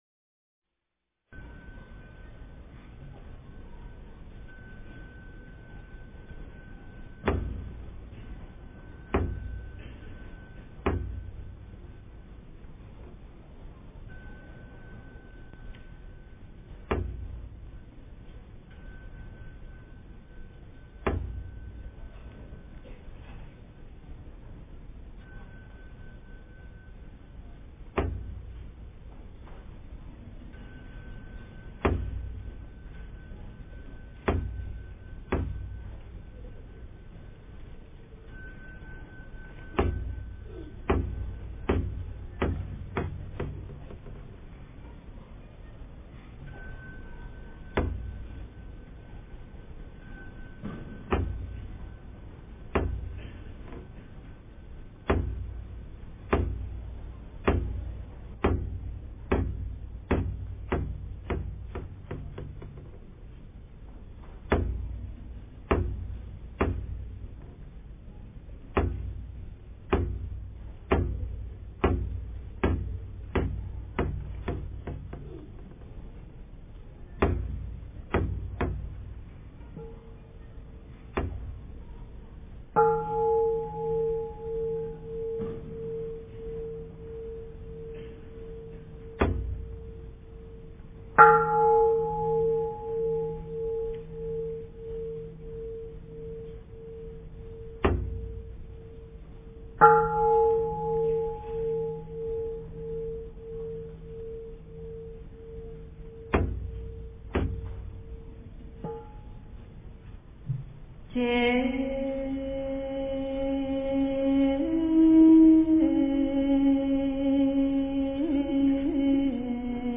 大方广佛华严经华严普贤行愿忏-上--僧团 经忏 大方广佛华严经华严普贤行愿忏-上--僧团 点我： 标签: 佛音 经忏 佛教音乐 返回列表 上一篇： 药师灌顶真言--如是我闻 下一篇： 大方广佛华严经华严普贤行愿忏-下--僧团 相关文章 南无大悲观世音--男声缓慢版 南无大悲观世音--男声缓慢版...